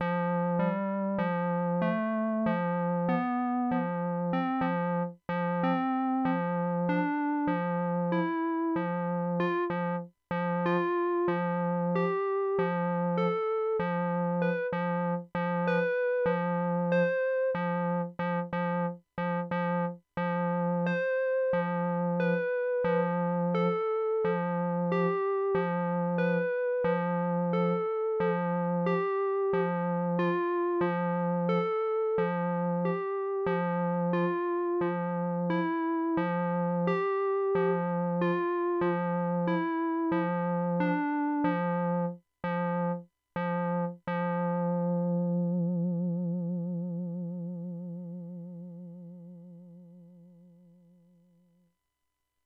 Typical RPG music. Synth keyboard improvisation.